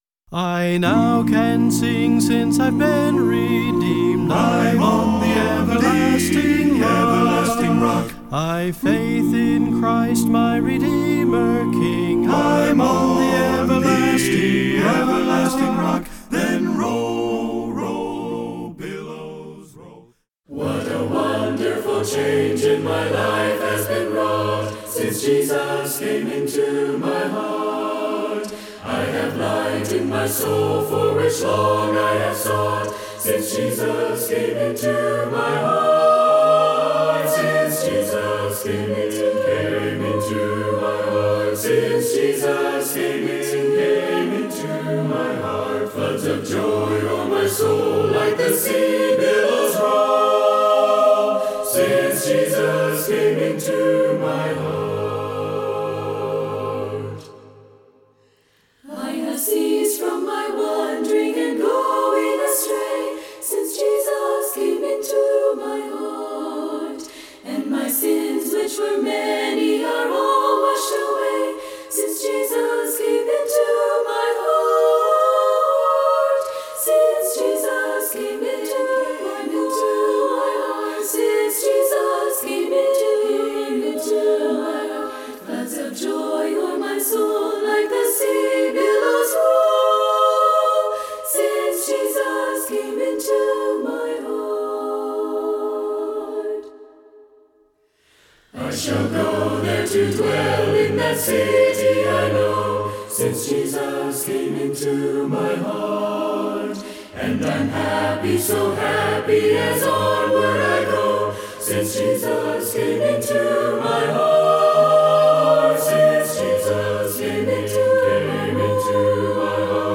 As Christ-followers, we can have absolute confidence that we truly have “A Word from God.” That is the title of my teaching on Revelation 1:4-8.